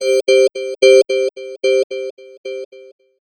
Index of /90_sSampleCDs/USB Soundscan vol.51 - House Side Of 2 Step [AKAI] 1CD/Partition D/02-FX LOOPS